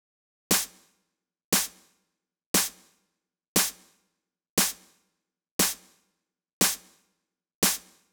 28 Snare PT1.wav